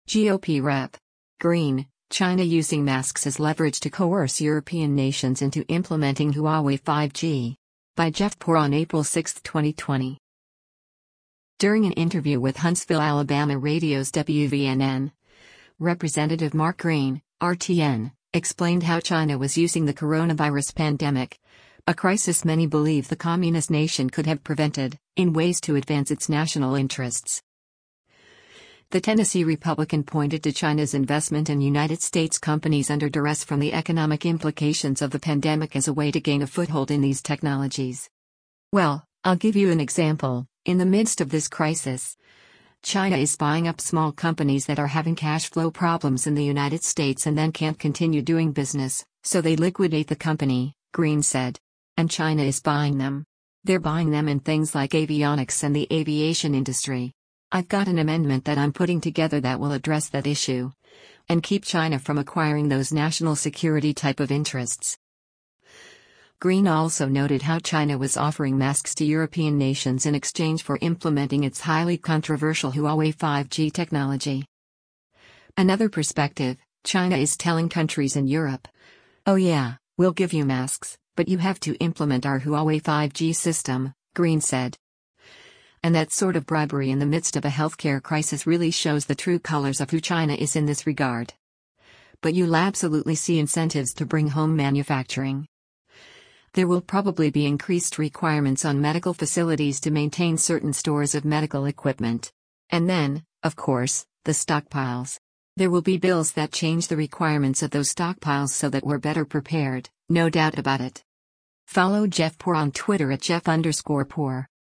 During an interview with Huntsville, AL radio’s WVNN, Rep. Mark Green (R-TN) explained how China was using the coronavirus pandemic, a crisis many believe the communist nation could have prevented, in ways to advance its national interests.